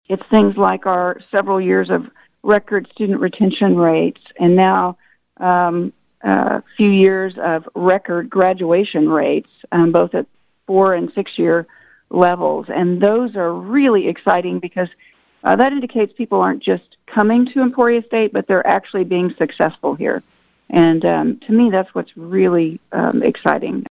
Because of COVID-19 protocols, Garrett appeared by phone Thursday for her final time on KVOE's ESU Buzz as she departs to become chancellor and CEO of the Oklahoma State System of Higher Education.